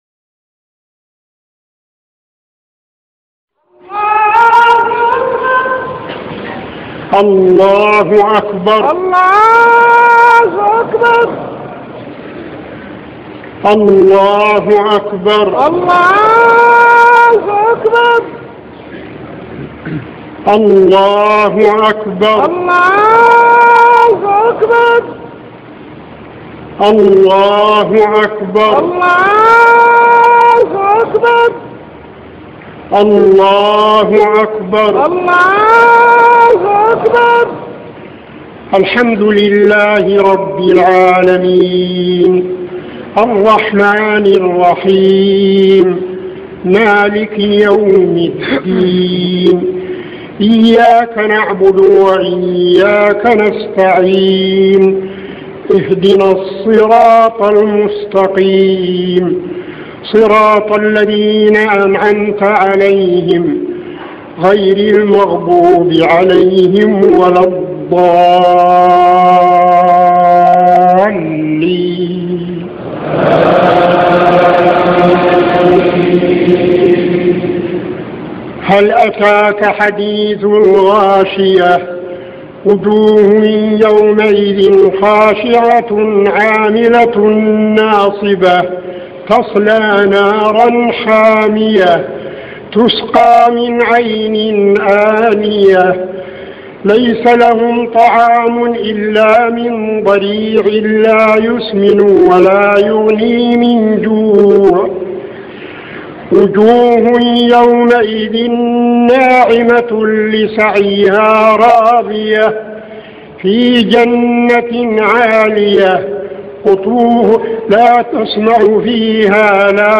صلاة العيد ١٤٠٩ من الحرم النبوي > 1409 🕌 > الفروض - تلاوات الحرمين